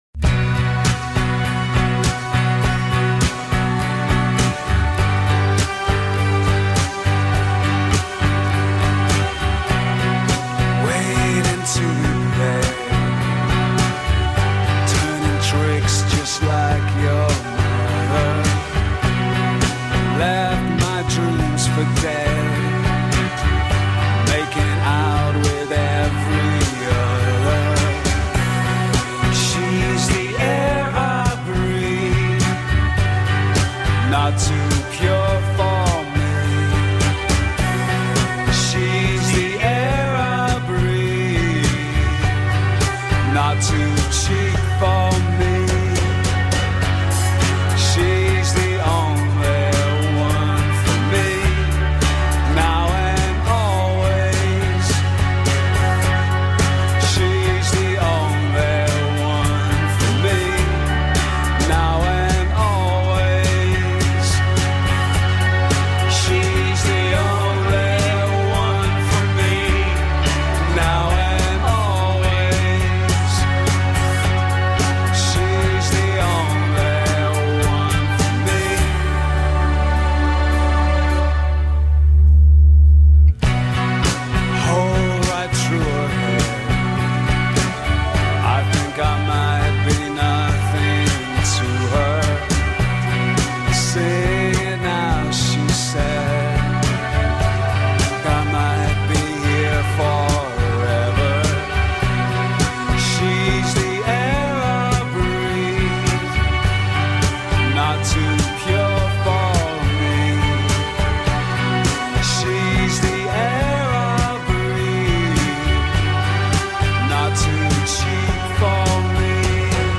Irish indie